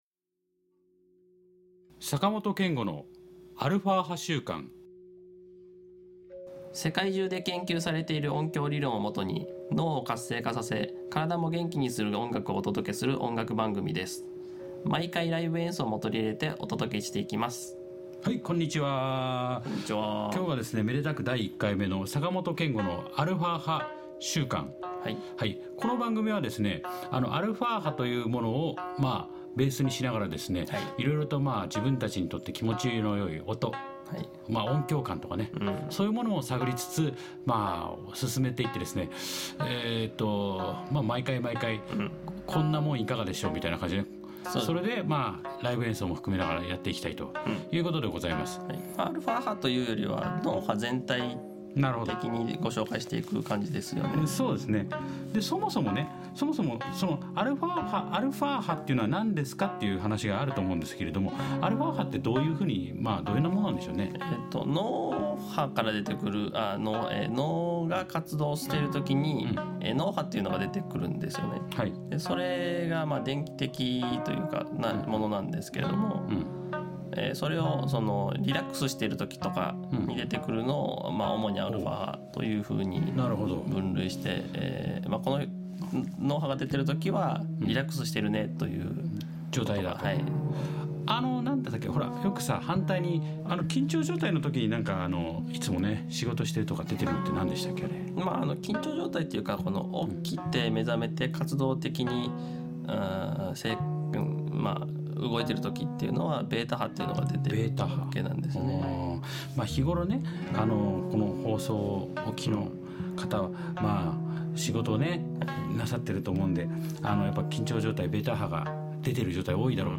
前半はジャワ・ガムラン（グンデル）の音をオープニングにお届けしていきます。
2013年5月15日にお届けした「週刊 みねいニュース」の中で取り上げた、記憶の定着が良くなる音源を使って、後半のライブ演奏をおおくりします。